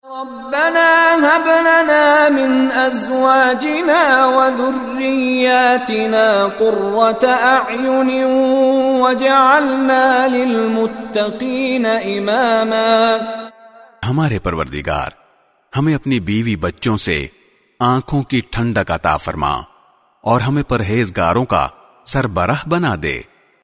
Rabbana hablana min azwajina wa zurriyatina qurrata - Full dua audio with urdu translation
Shaykh Mishari Rashid Al Afasy is one of the world\'s most popular Quran Reciters. His melodious voice and impeccable tajweed are perfect for any student of Quran looking to learn the correct recitation of the holy book.